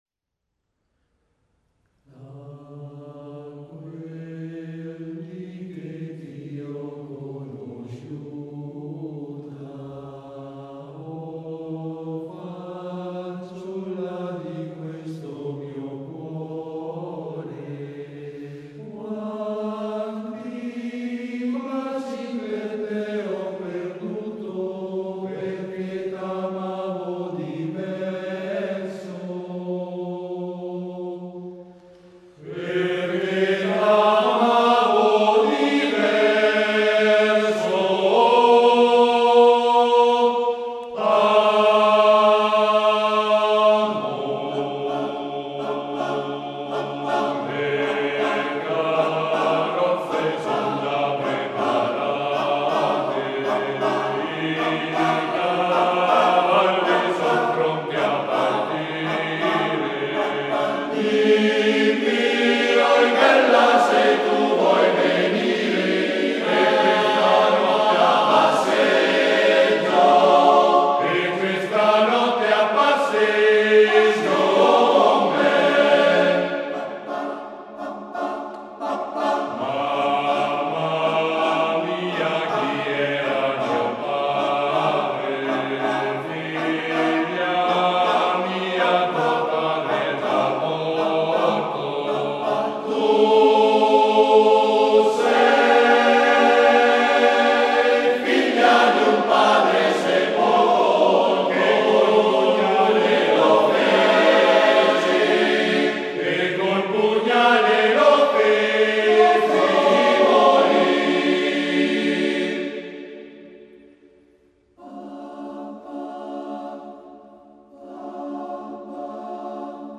Arrangiatore: Dionisi, Renato (armonizzatore)
Esecutore: Coro SOSAT